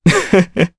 Crow-Vox_Happy2_jp.wav